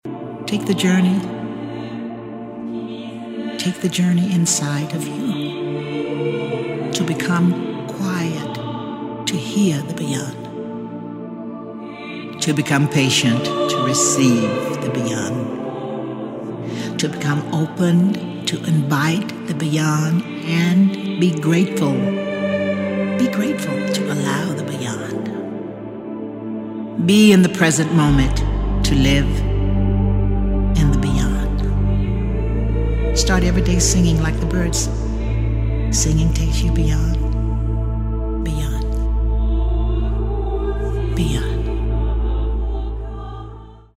The singer speaks her spiritual message.